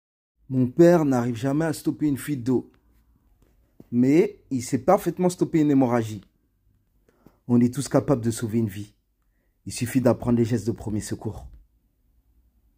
Bandes-son
Coca voix off
20 - 30 ans - Baryton